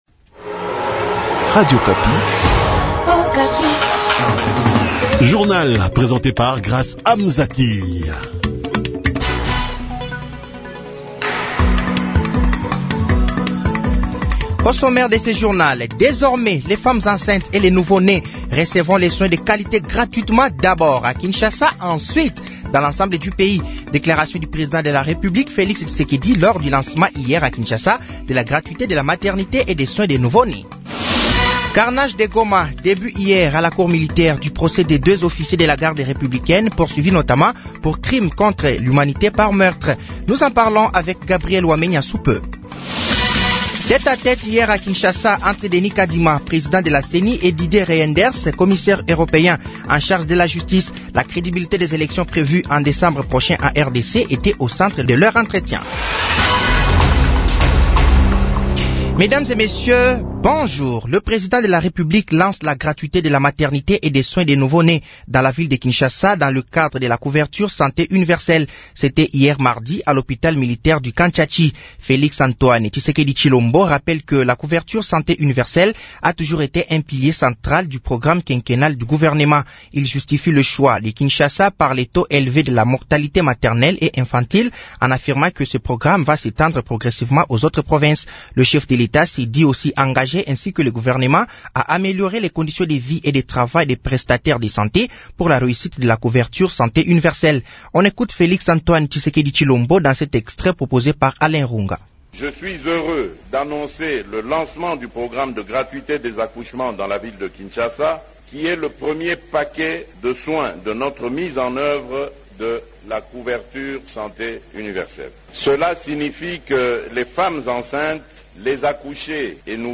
Journal français de 7h de ce mercredi 06 septembre 2023